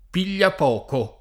[ pil’l’ap 0 ko ]